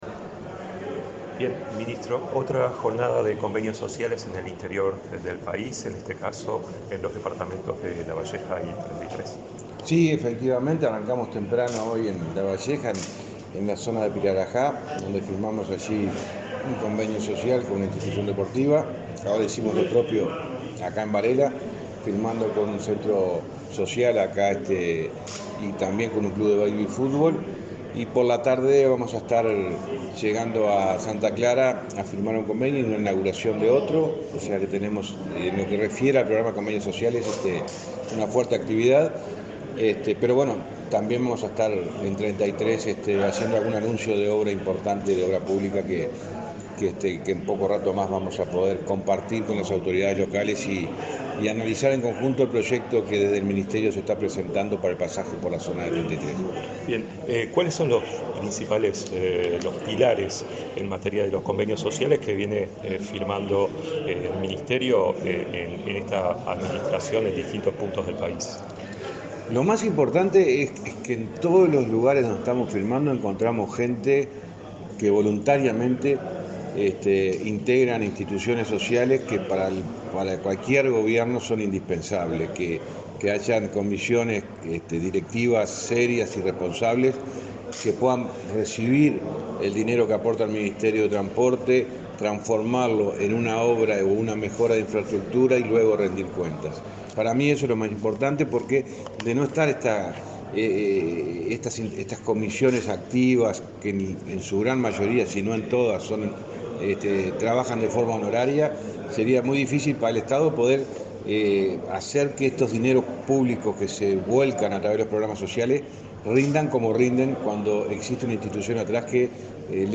Declaraciones del ministro de Transporte, José Luis Falero
El ministro de Transporte, José Luis Falero, dialogó con la prensa antes de inaugurar obras realizadas por convenios sociales en Lavalleja y Treinta